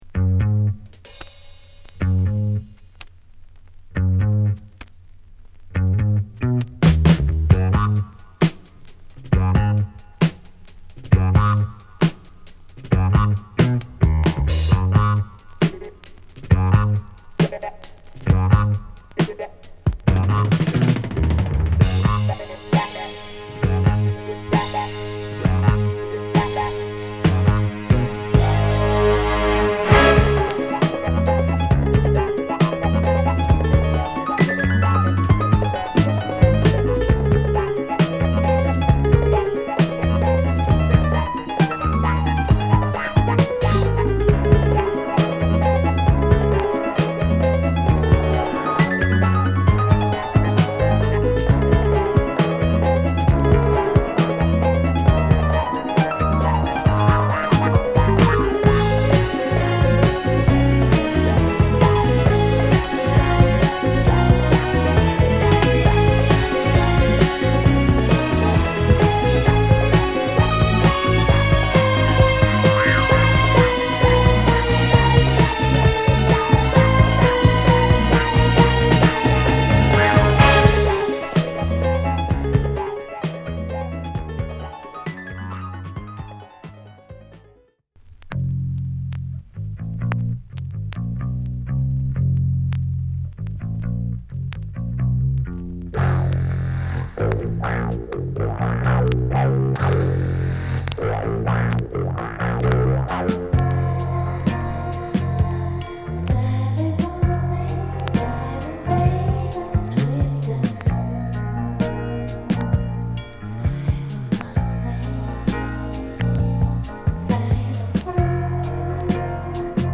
＊プチノイズ入ります。